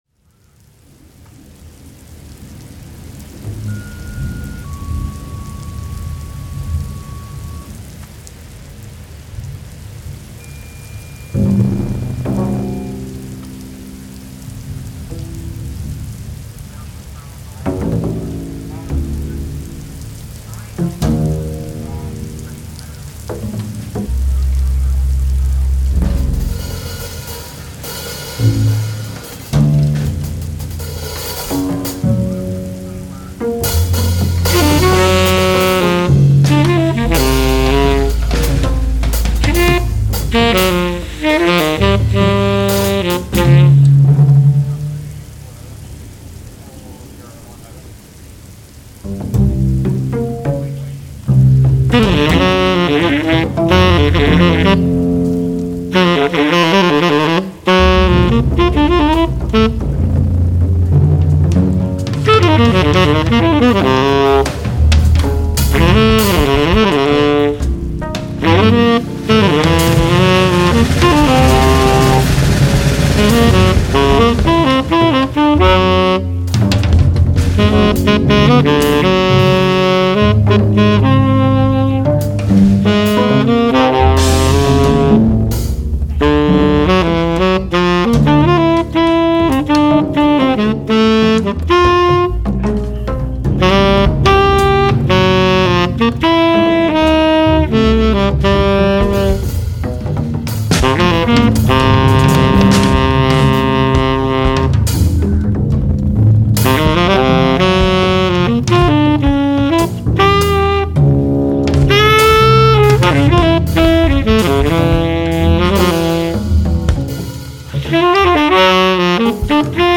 Additional (live mashed) sonic fictions